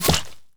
bullet_impact_mud_02.wav